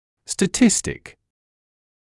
[stə’tɪstɪk][стэ’тистик]статистический